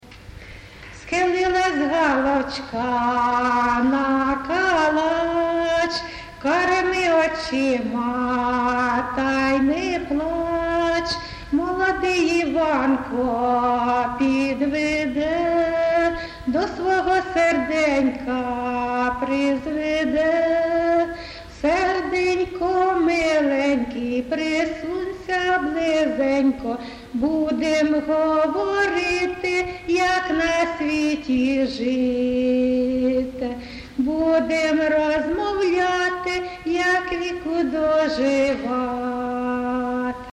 ЖанрВесільні
Місце записус-ще Зоря, Краматорський район, Донецька обл., Україна, Слобожанщина